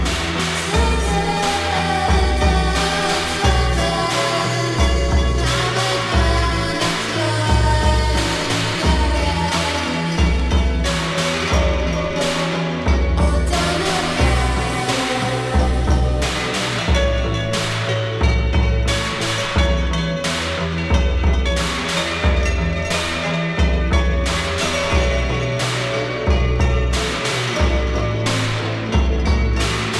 de tradição gótica apurada
as caixas de ritmos não perdoam na cadência marcial